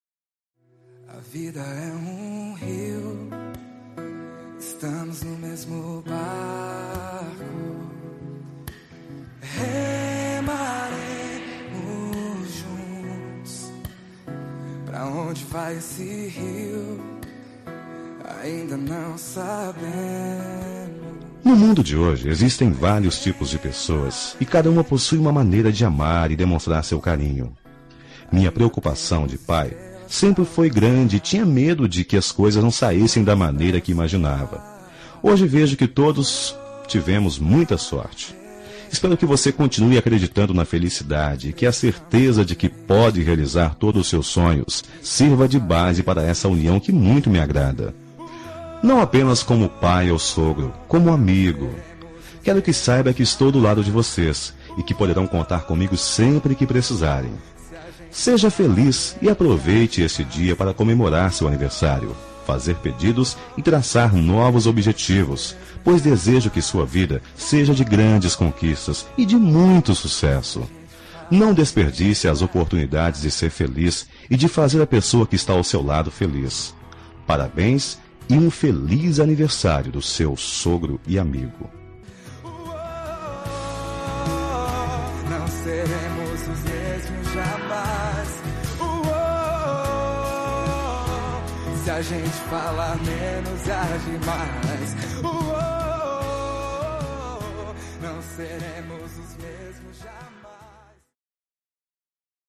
Aniversário de Genro – Voz Masculina – Cód: 348963